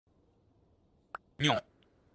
leaked-minecraft-piglin-sound-effect.mp3